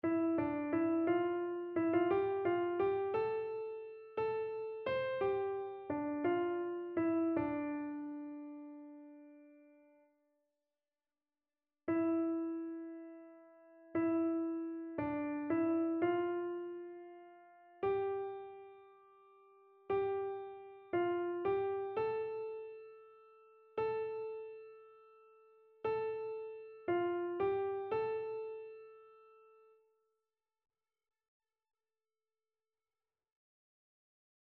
Chœur
annee-a-temps-ordinaire-11e-dimanche-psaume-99-soprano.mp3